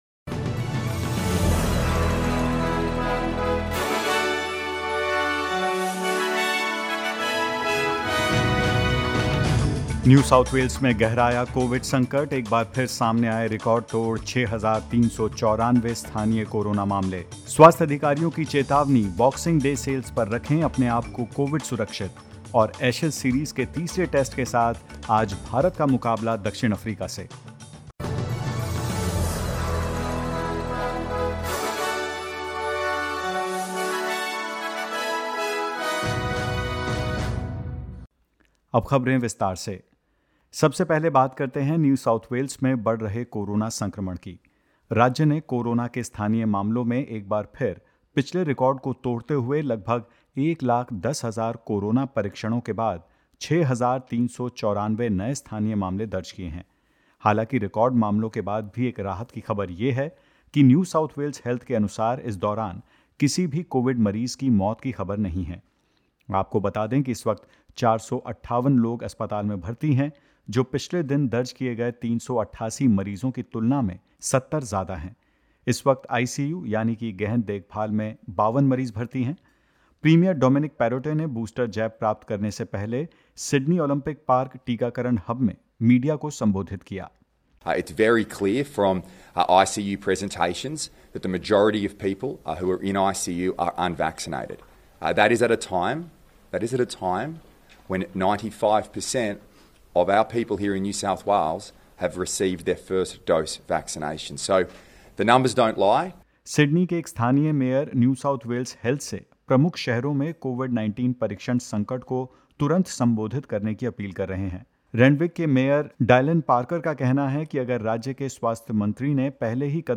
In this latest SBS Hindi news bulletin: Record online spending expected for Boxing Day Sales as some people opt to avoid potential super spreader gatherings; Queensland Health says three-quarters of COVID-19 patients in Queensland in the last two weeks have been under the age of 35 and more.